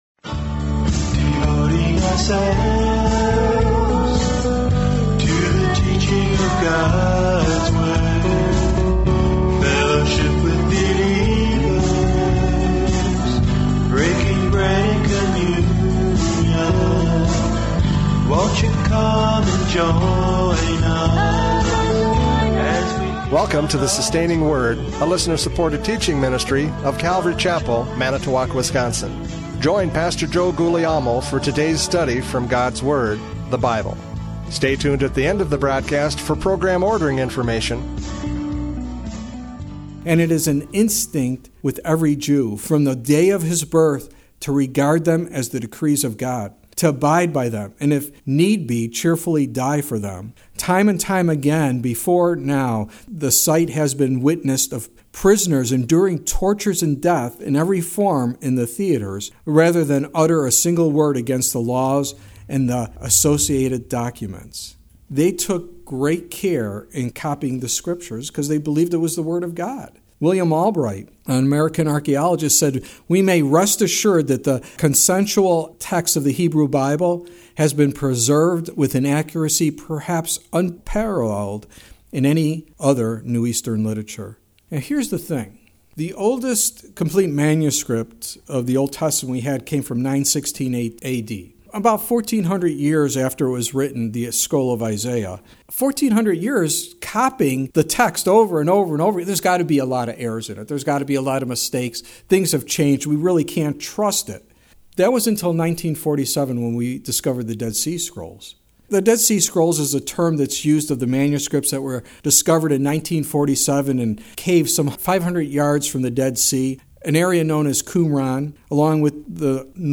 John 12:9-11 Service Type: Radio Programs « John 12:9-11 Destroying the Evidence!